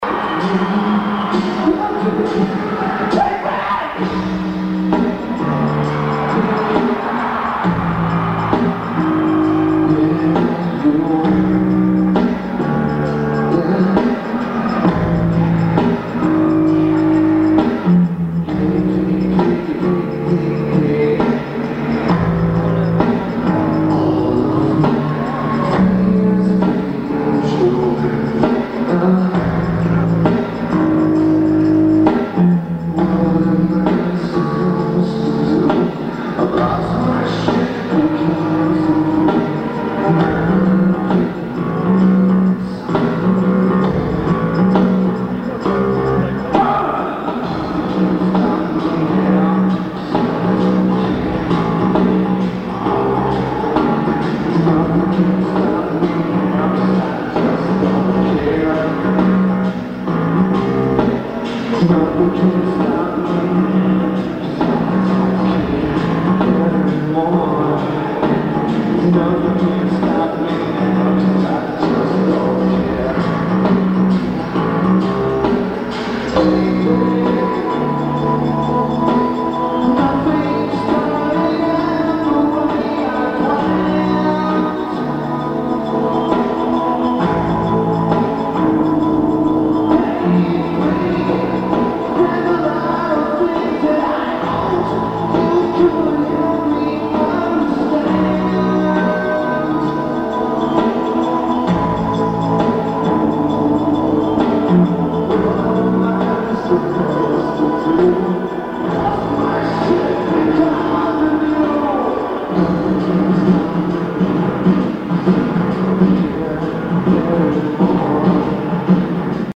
Hampton Coliseum
Lineage: Audio - AUD (Sony WM-D3 + Supplied Sony Mic)